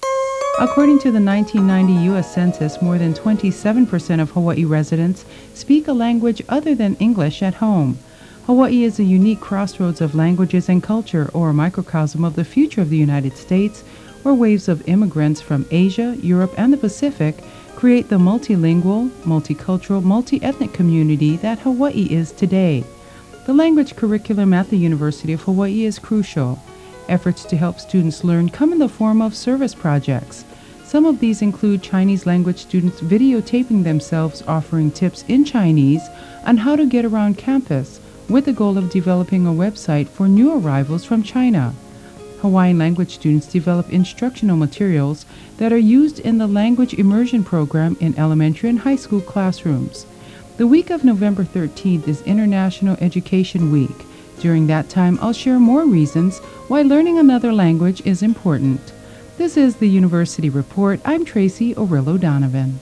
Samples of Public Service Announcements (PSA) are available in WAV format: